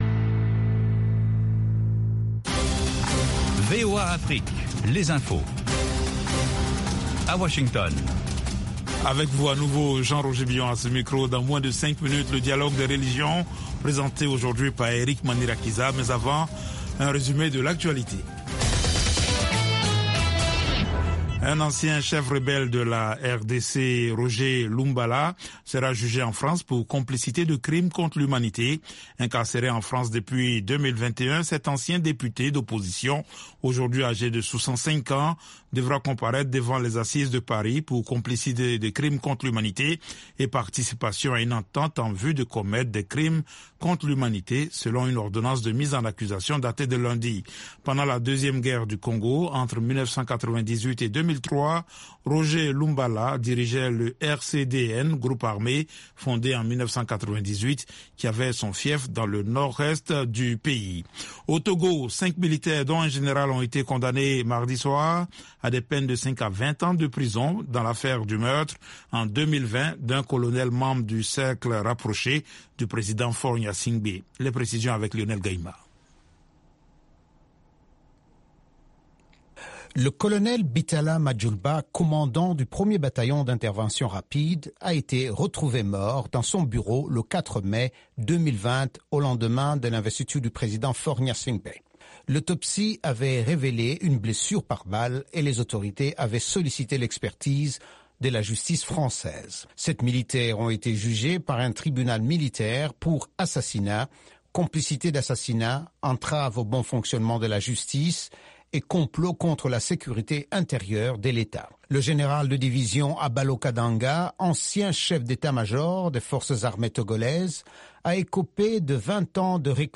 3 min Newscast